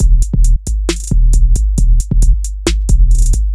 神秘的陷阱节拍循环
描述：这个自由陷阱节拍是一个28秒的精细循环。
bpm是140。
标签： 街舞 说唱 陷阱 悬念 节拍 神秘
声道立体声